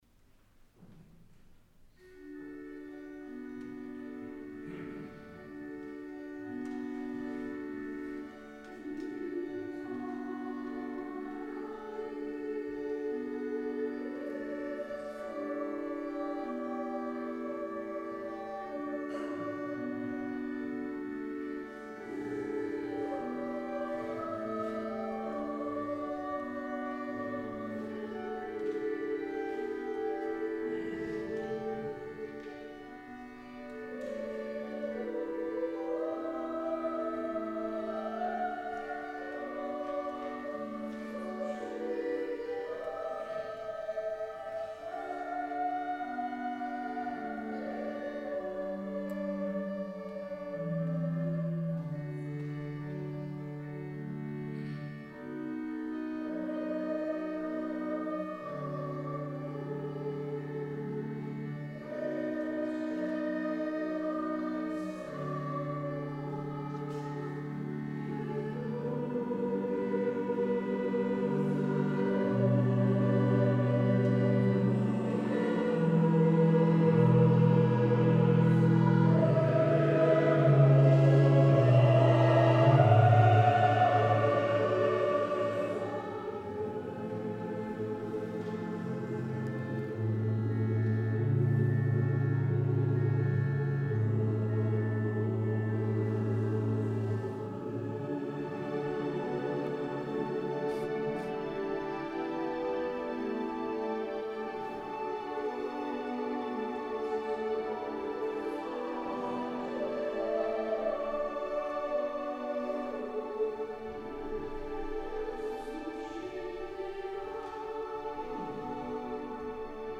S. Gaudenzio church choir Gambolo' (PV) Italy
Dal concerto del 30 Aprile 2016